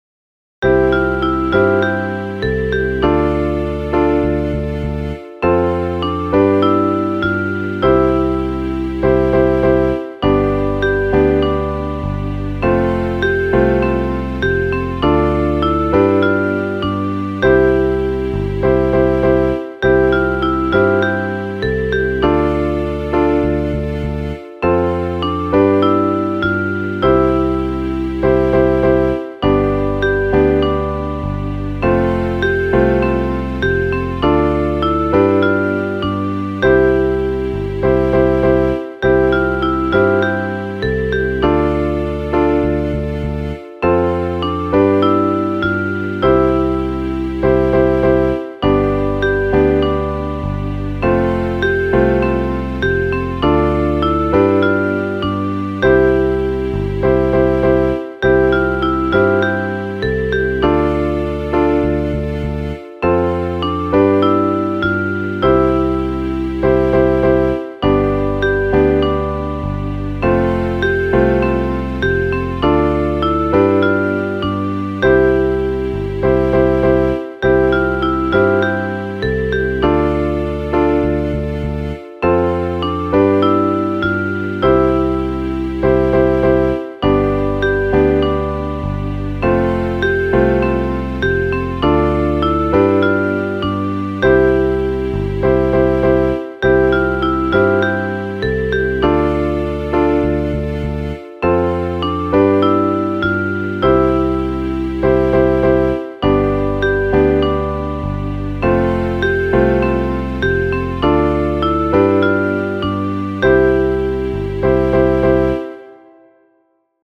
Piano Ensemble